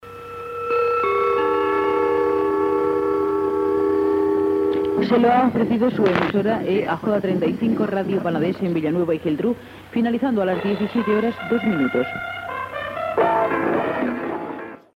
Identificació de l'emissora i hora